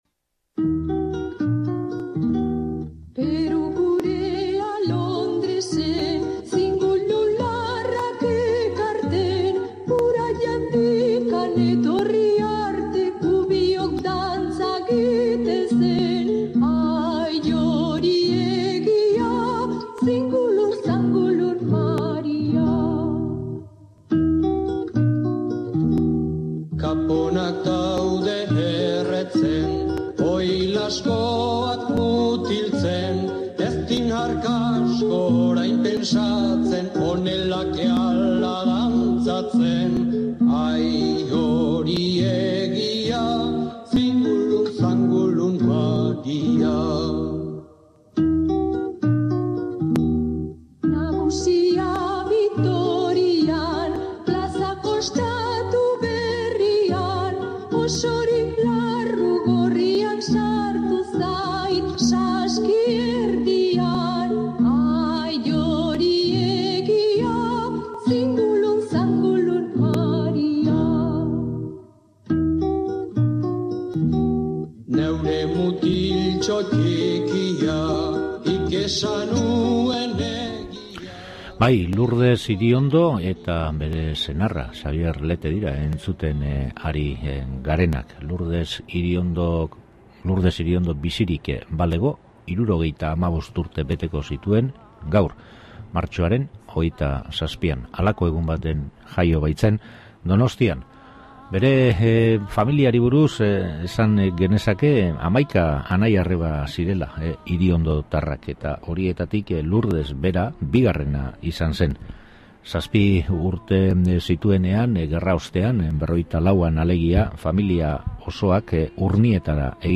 SOLASALDIA
1937ko martxoaren 27an sortu zen Lourdes Iriondo Donostian; besteak beste “Ez Dok Amairu” mugimenduko sortzaileetakoa izan zen kantari apartaren bizitzaren ibilbidean murgildu gara; zenbait kanta ere bere ahotsean entzuteko aukera duzu gaurkoan entzule.